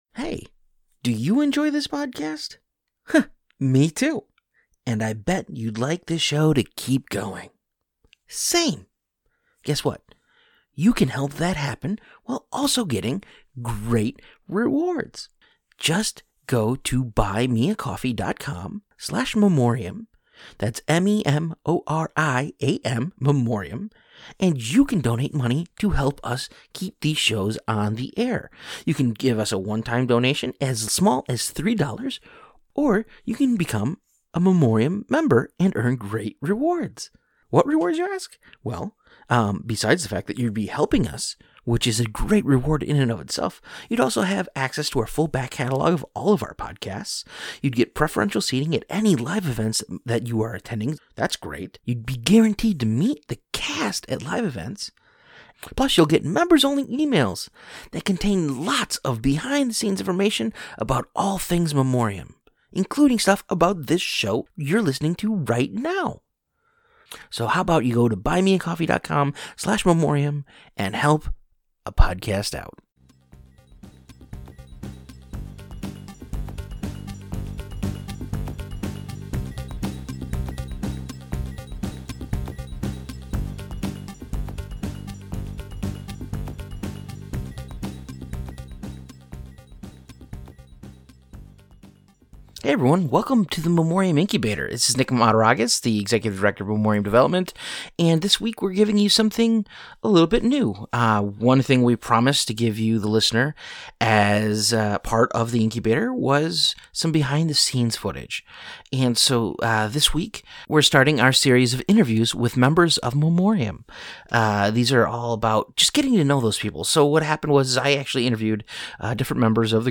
Memoriam Interviews 001 &#8211